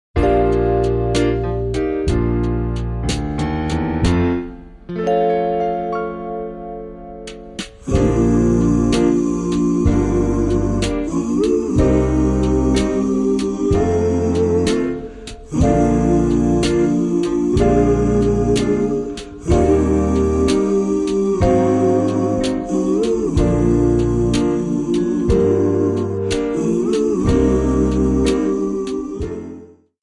Backing track files: 1950s (275)